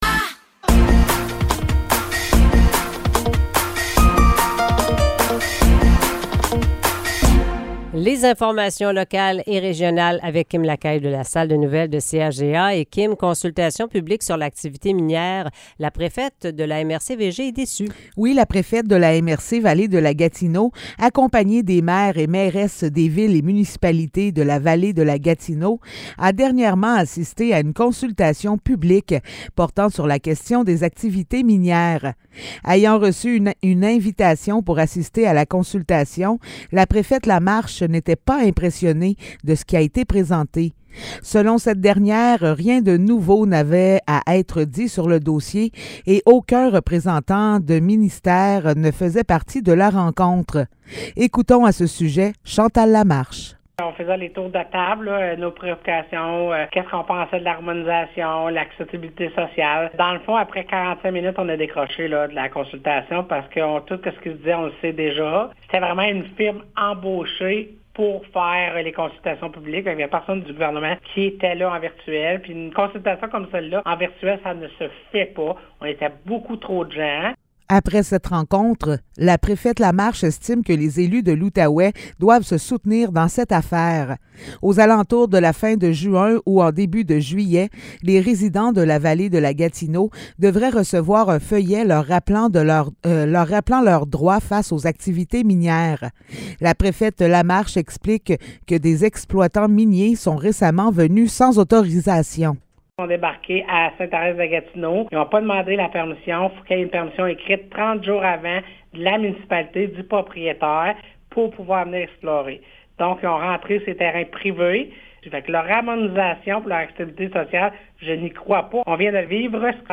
Nouvelles locales - 30 mai 2023 - 7 h